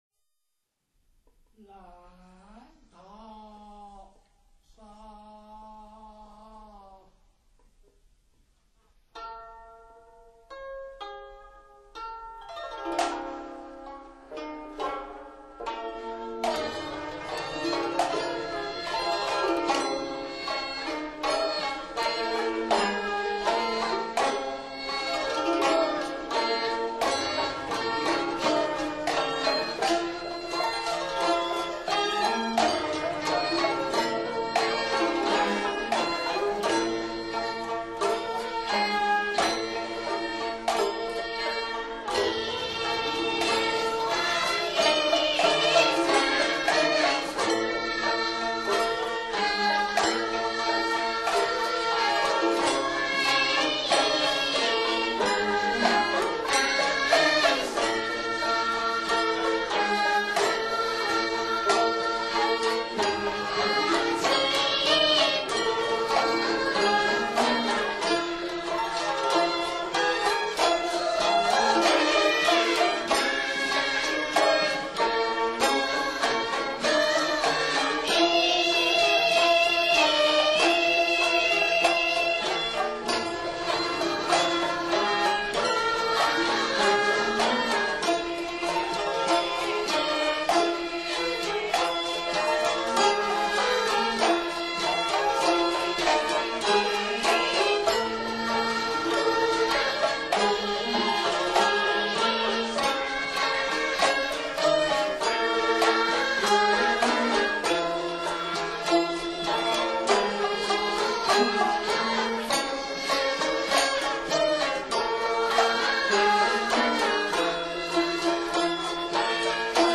古乐会演奏的乐曲历史悠久，古朴典雅，最早可追溯至唐代。
曲牌